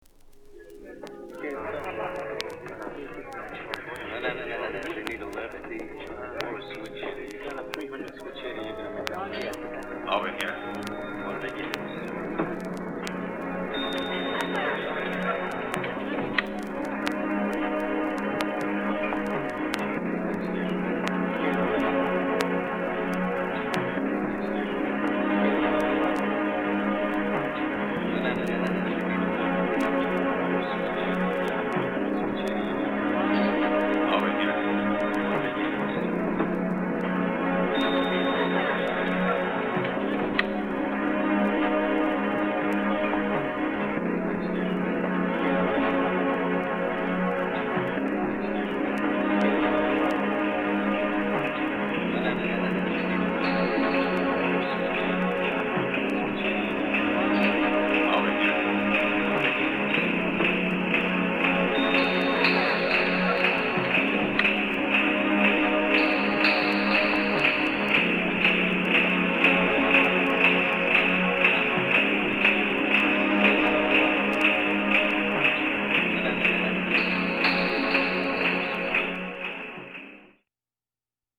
マシーナリーなビートダウン要素も垣間見れるトラックになっています。
House / Techno